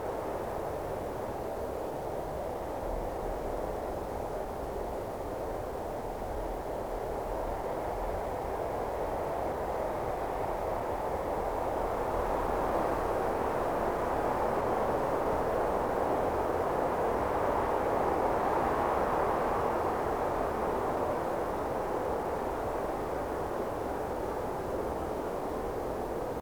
Ambient_Stereo_01.ogg